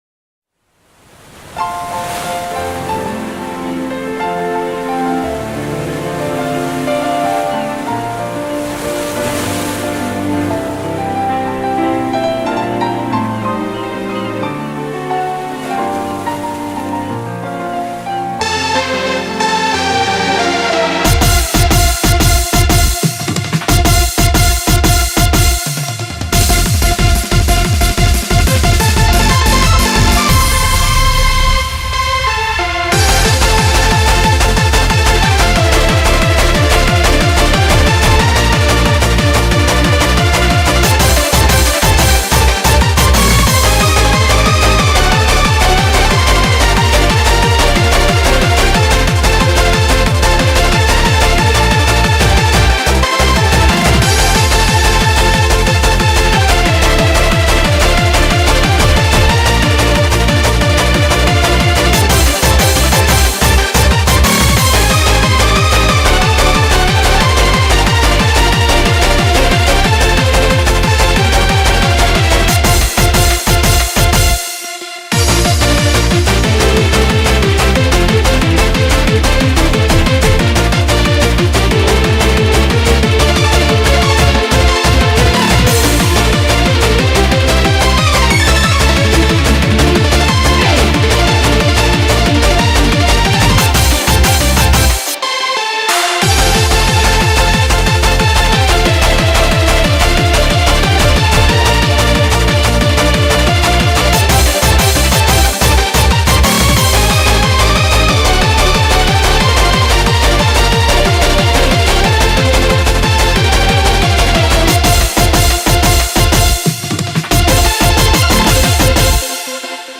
BPM182
Comments[VACATION DANCE]